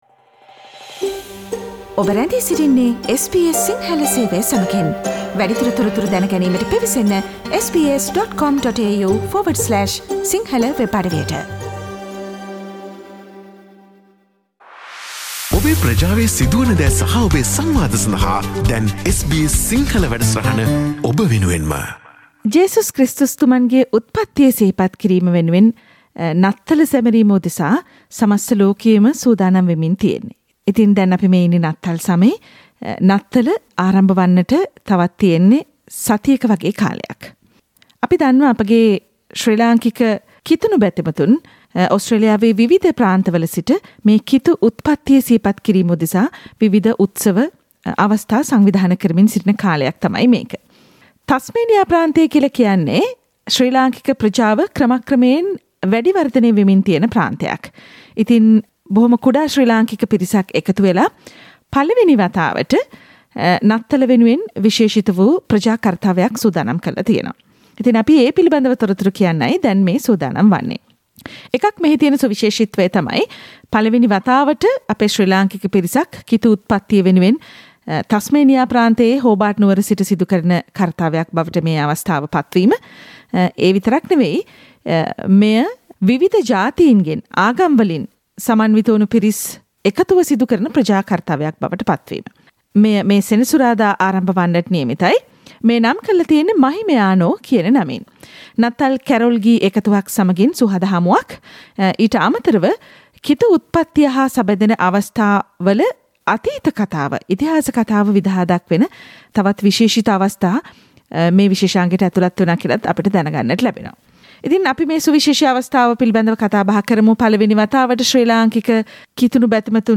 SBS Sinhala Radio interview